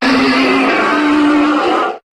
Cri de Brutapode dans Pokémon HOME.